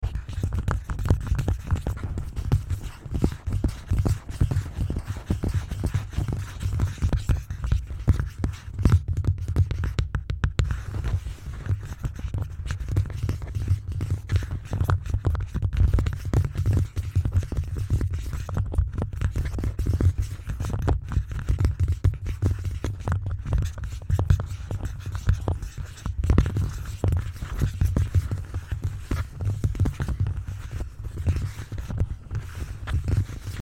Taps and scratches to help sound effects free download
Taps and scratches to help you relax after a busy day 😴.